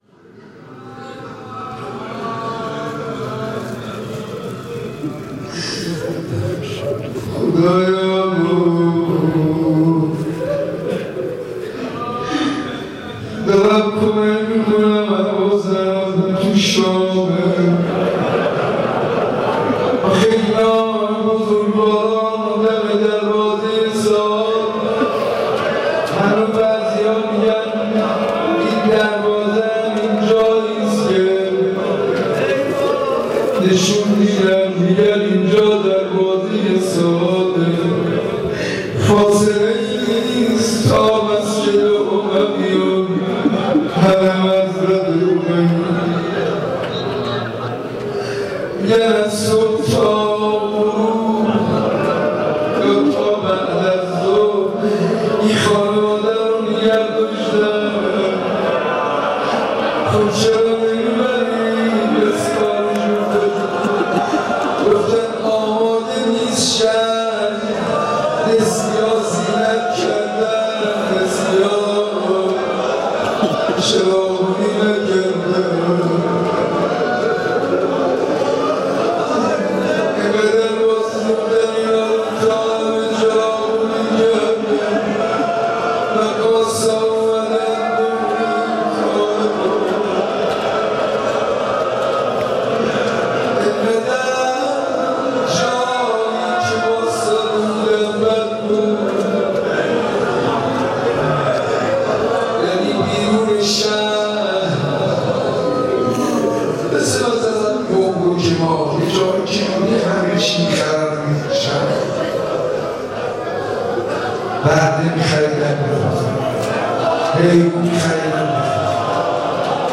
روضه ورود کاروان اسرا به شام
در مراسم هفتگی دعای کمیل حرم حضرت عبدالعظیم(ع) سحرگاه روز آخر محرم